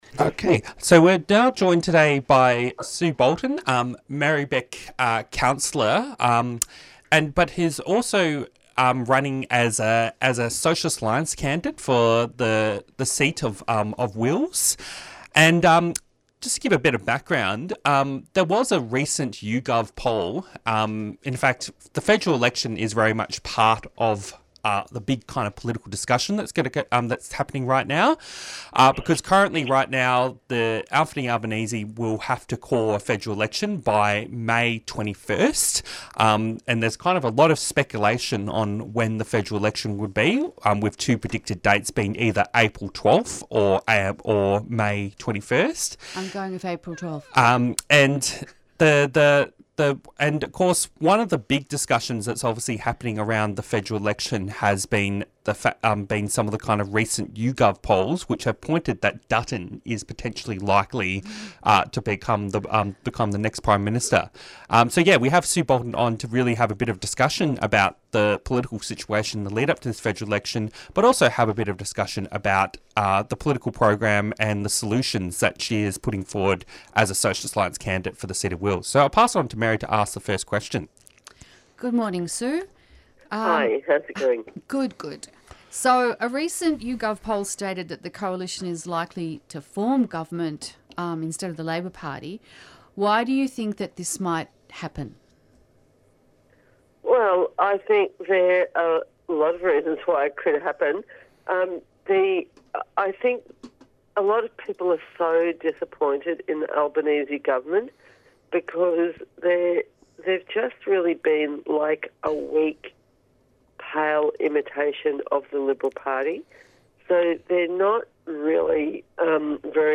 Interviews and Discussion